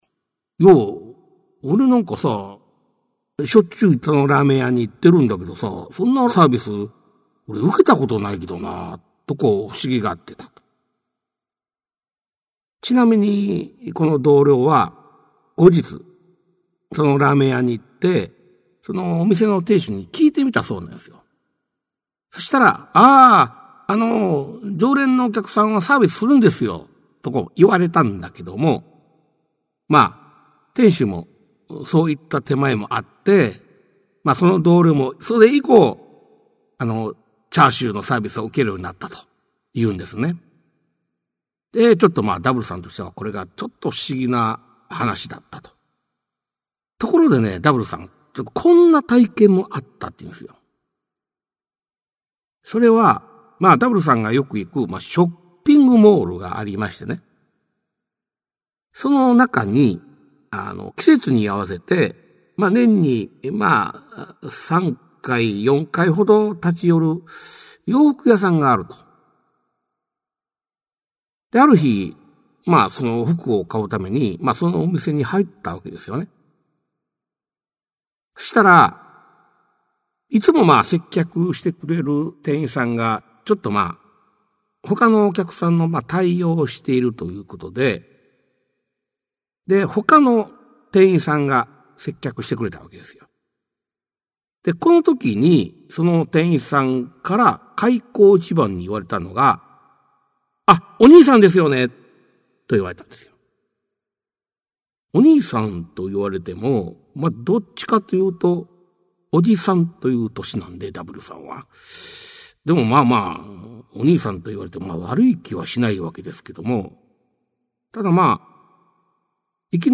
[オーディオブック] 市朗怪全集 五十八
実話系怪談のパイオニア、『新耳袋』シリーズの著者の一人が、語りで送る怪談全集! 1990年代に巻き起こったJホラー・ブームを牽引した実話怪談界の大御所が、満を持して登場する!!
[演]中山市朗